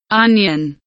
onion kelimesinin anlamı, resimli anlatımı ve sesli okunuşu